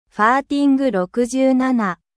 Farting 67 Efecto de Sonido Descargar
Farting 67 Botón de Sonido
farting-67.mp3